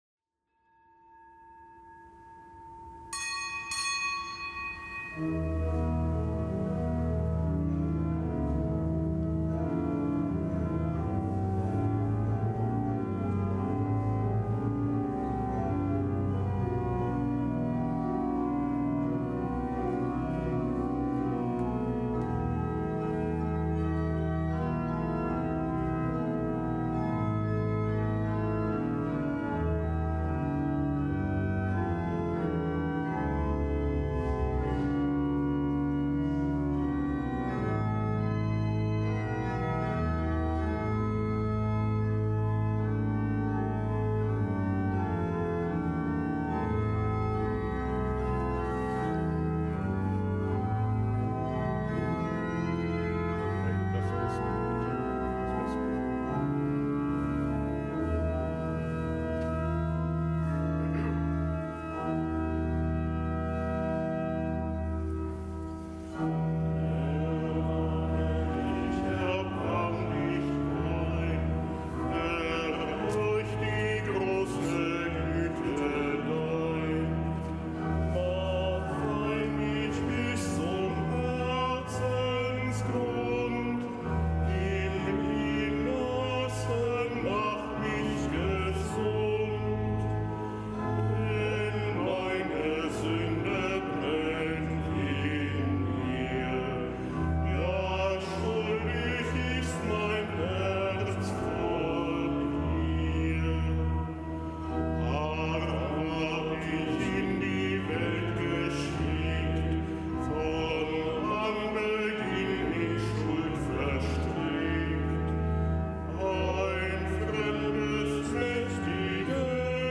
Kapitelsmesse am Aschermittwoch
Kapitelsmesse aus dem Kölner Dom am Aschermittwoch.